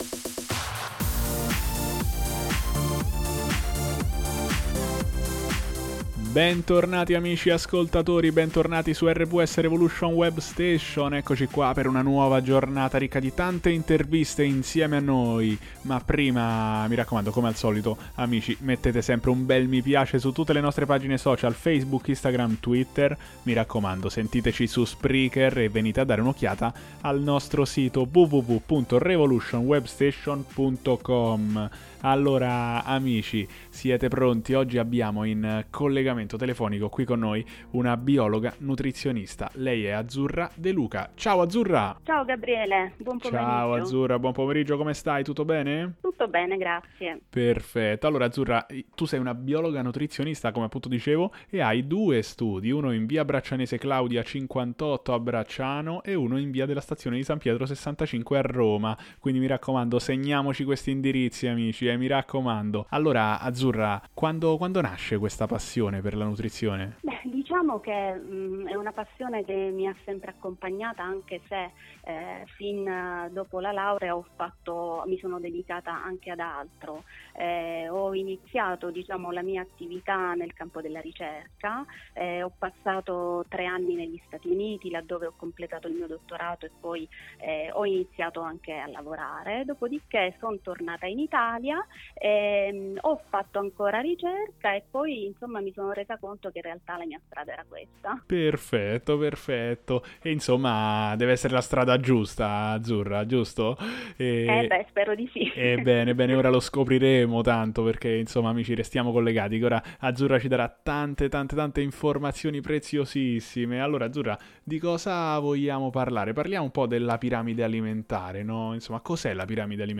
In questa intervista a Revolution Web Station Revolution Web Station parlo della piramide alimentare della dieta mediterranea. Spiego perché la dieta mediterranea è cosi importante, quali sono gli alimenti da preferire e quali quelli che sarebbe meglio evitare nella nostra alimentazione quotidiana.